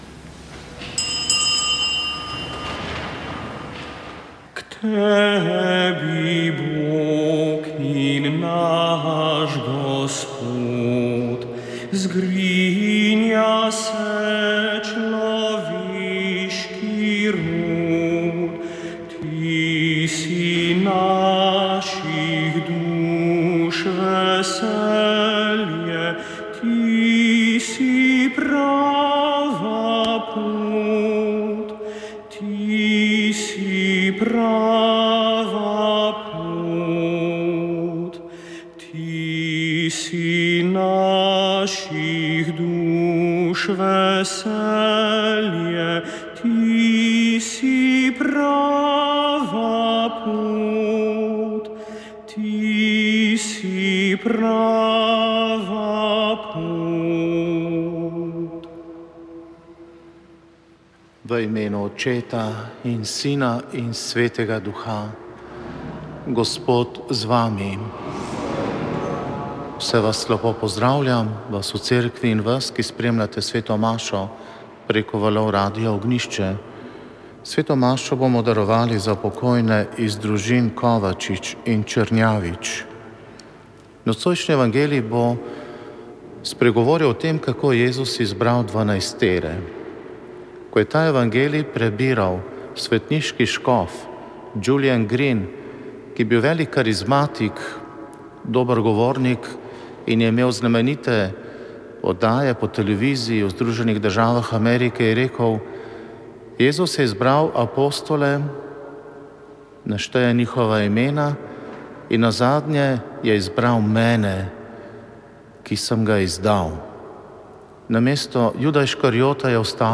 Sveta maša
Sv. maša iz cerkve Marijinega oznanjenja na Tromostovju v Ljubljani 28. 10.